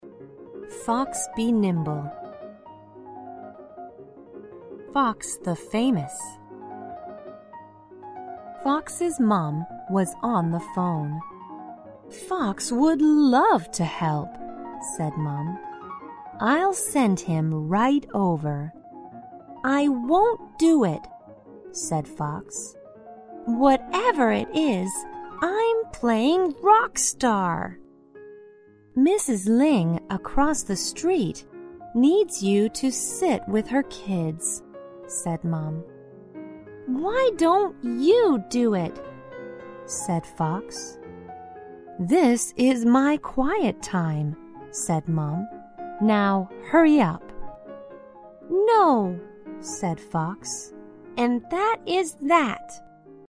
在线英语听力室小狐外传 第53期:灵活的小狐的听力文件下载,《小狐外传》是双语有声读物下面的子栏目，非常适合英语学习爱好者进行细心品读。故事内容讲述了一个小男生在学校、家庭里的各种角色转换以及生活中的趣事。